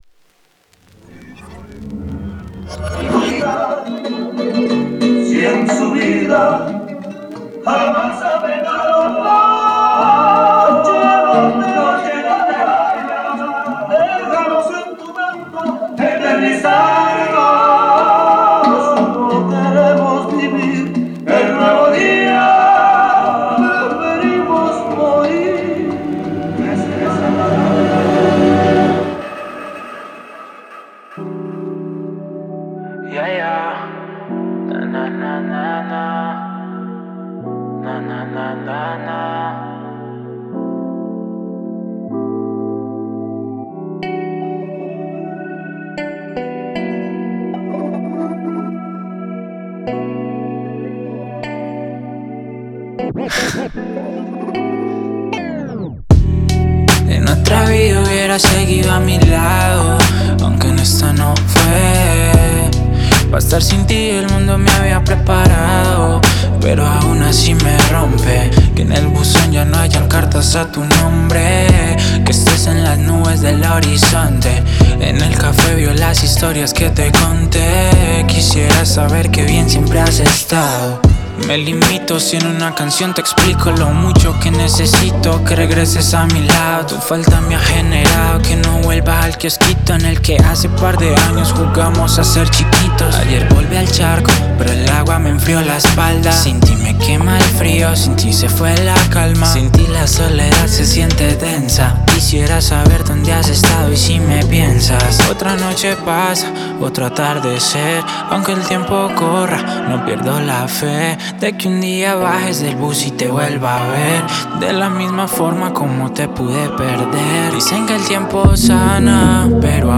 Música urbana